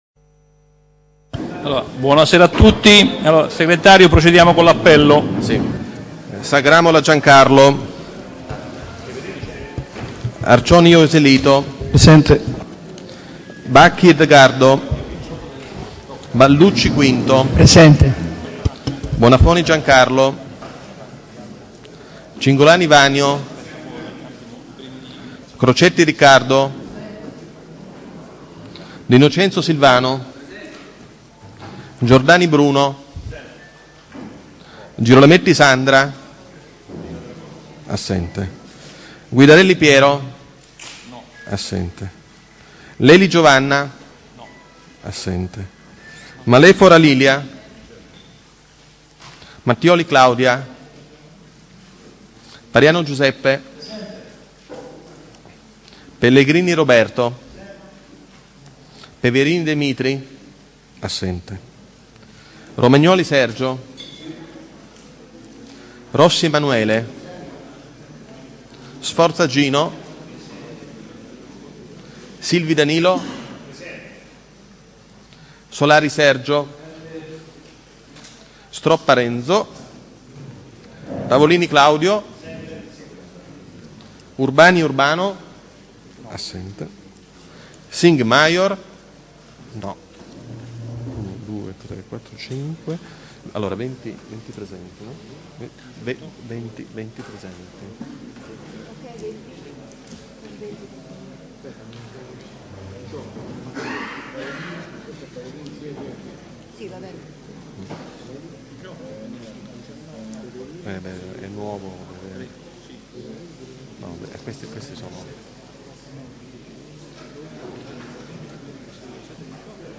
Ai sensi dell'art. 20, comma 7, dello Statuto Comunale e dell'articolo 14 del regolamento consiliare, il Consiglio Comunale è convocato presso Palazzo Chiavelli - sala consiliare martedì 27 settembre 2016 alle ore 18 ed occorrendo, in seconda convocazione per il giorno giovedì 29 settembre 2016 alle ore 18
AUDIO DELLE SEDUTA